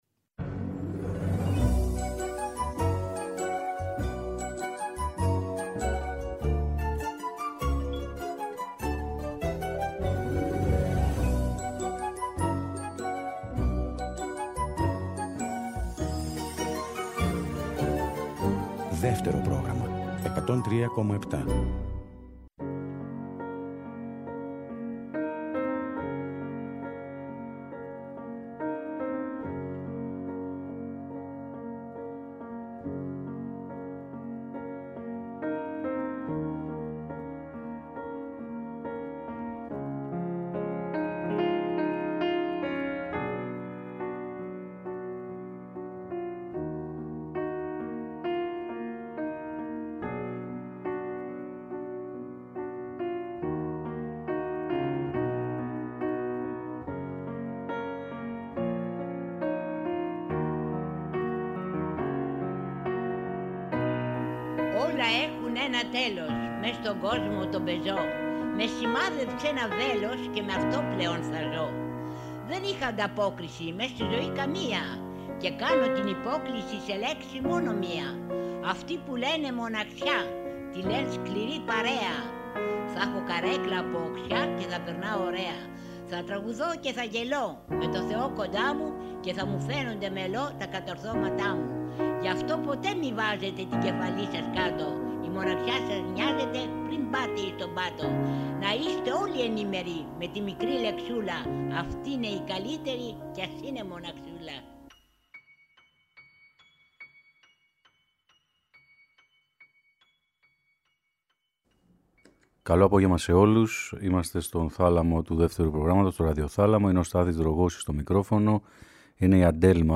Τραγούδια διαχρονικά αθάνατα και ηλεκτρικά